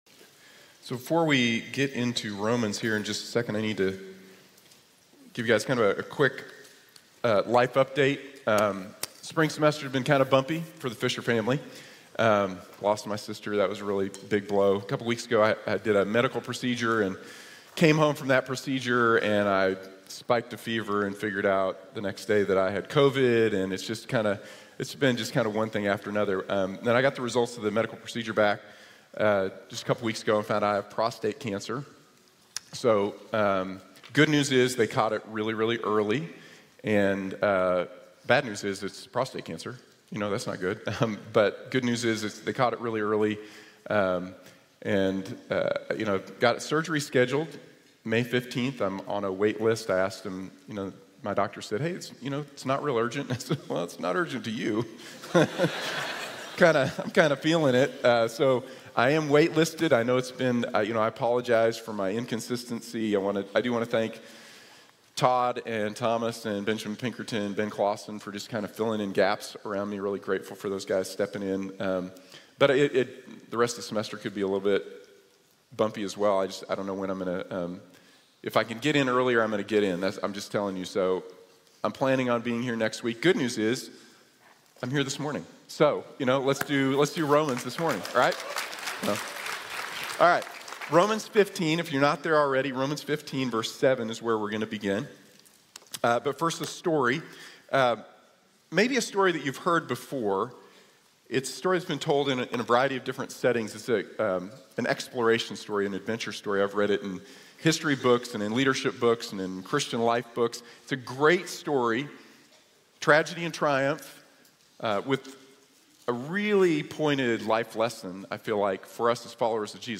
A Long Obedience in the Same Direction | Sermon | Grace Bible Church